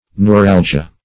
Neuralgia \Neu*ral"gi*a\, n. [NL., from Gr. ney^ron nerve + ?